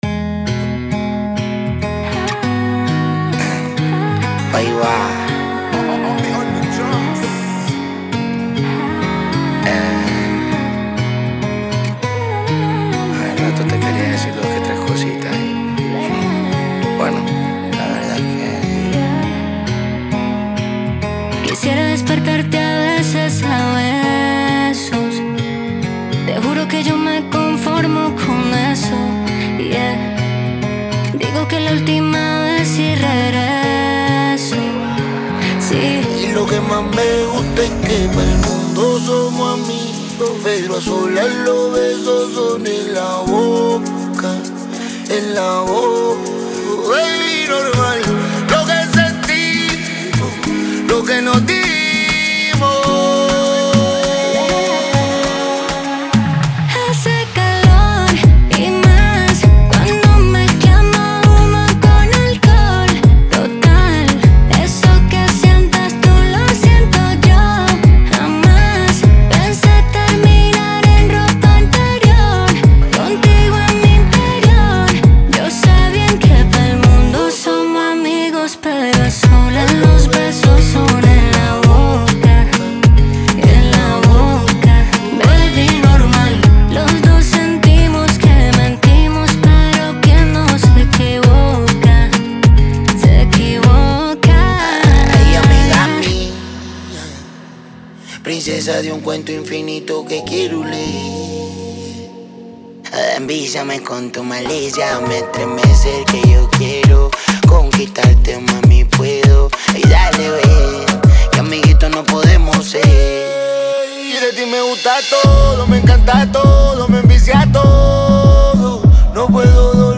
Genre: Latin.